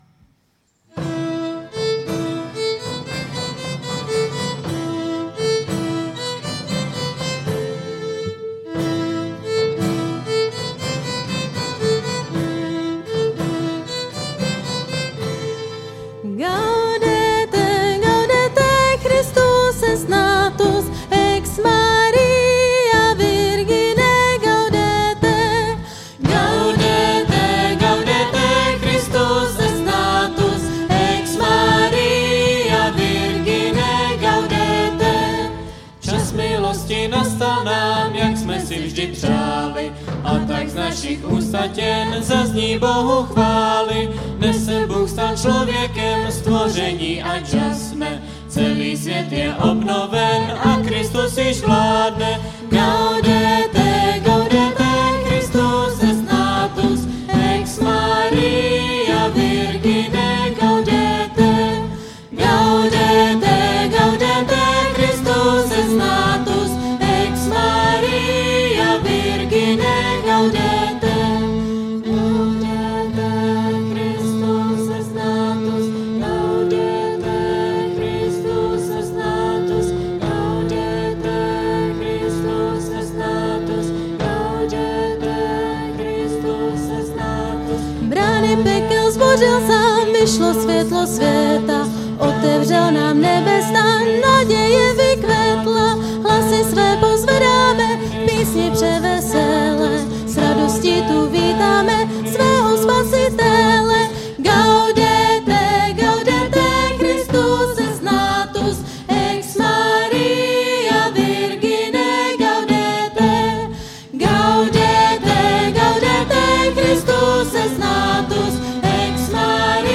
Půlnoční bohoslužba se zpěvem